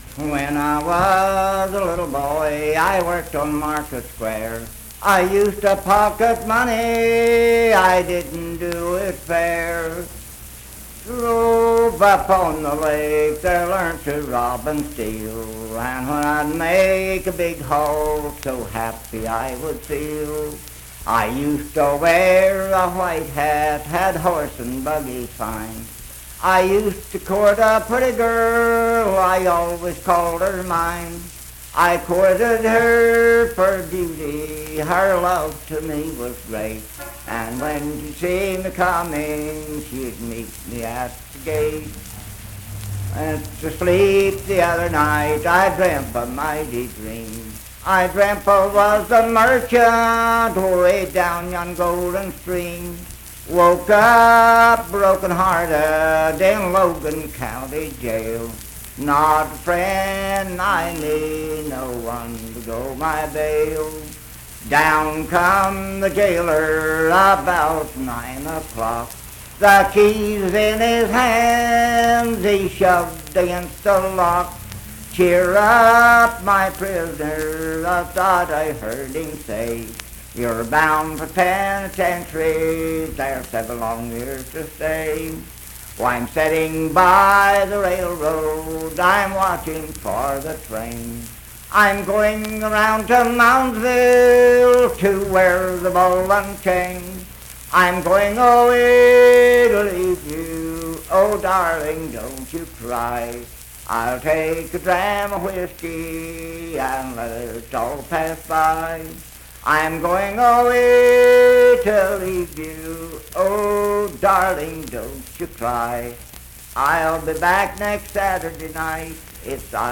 Unaccompanied vocal music performance
Verse-refrain 6d (4).
Voice (sung)